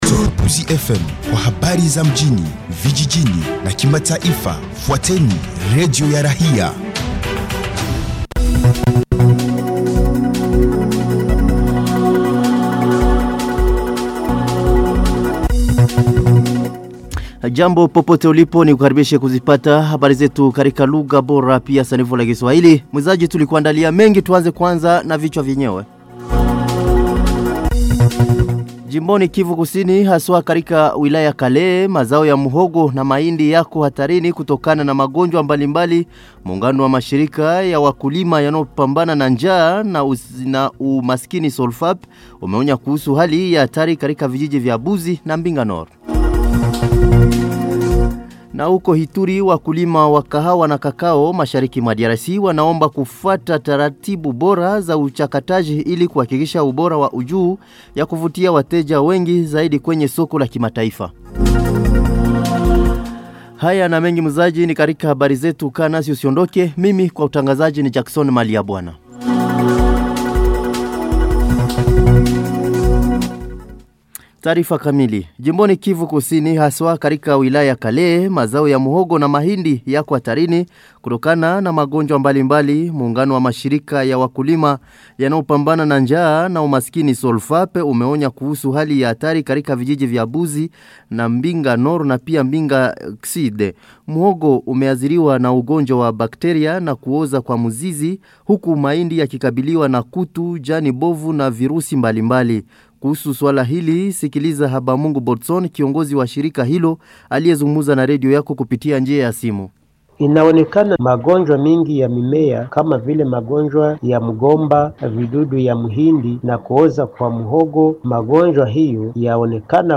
Journal swahili soir 12 janvier 2026